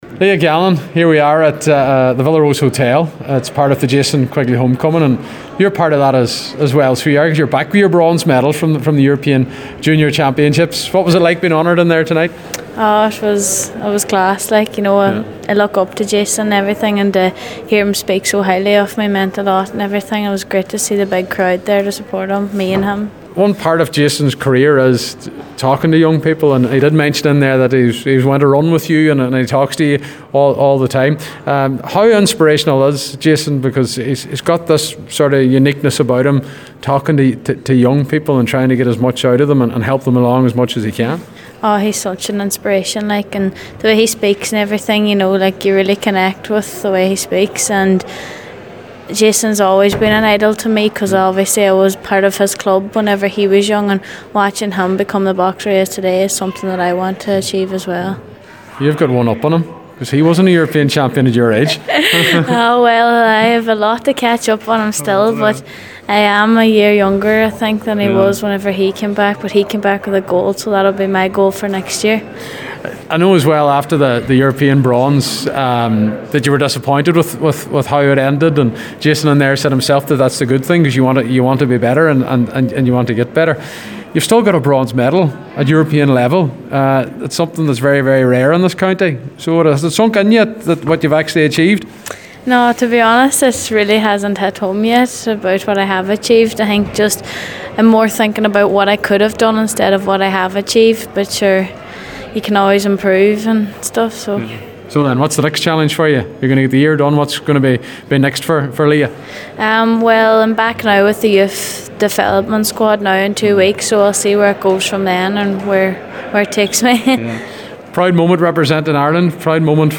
a few other Donegal people who attended the homecoming